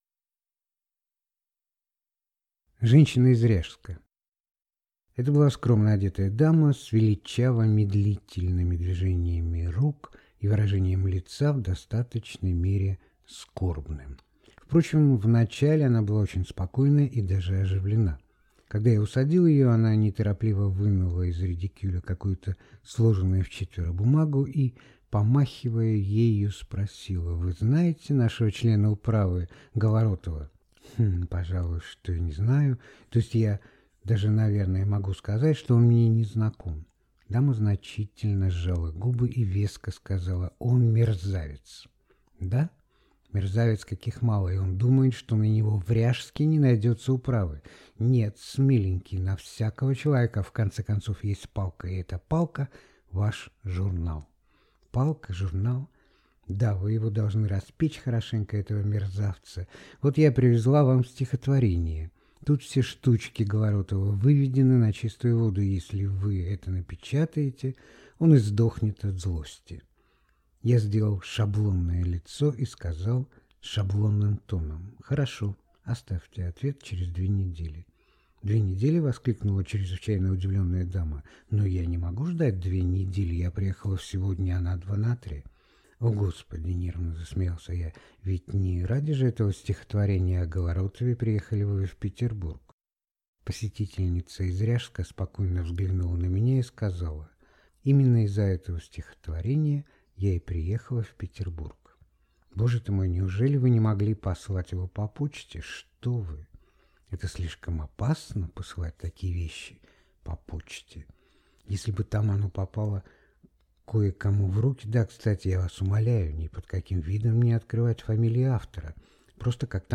Аудиокнига Три визита | Библиотека аудиокниг